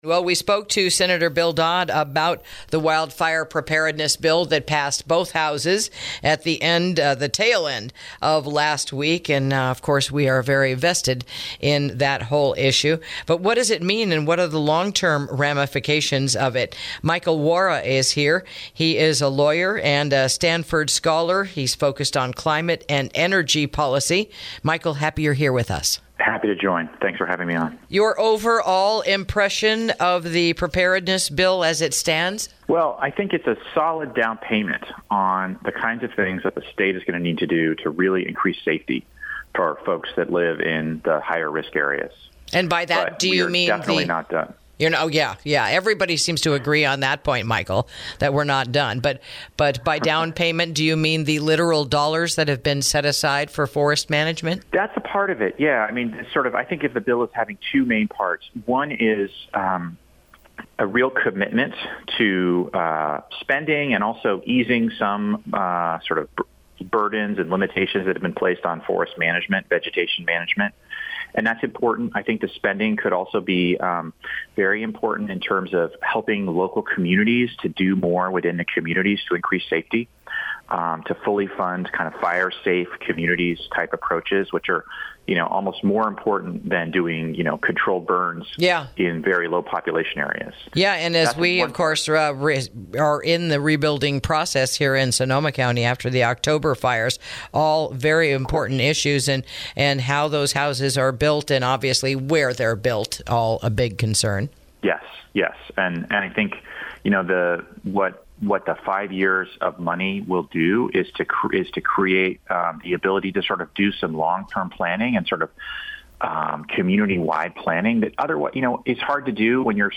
INTERVIEW: Another Look at the Wildfire Preparedness Bill Heading to Governor Brown’s Desk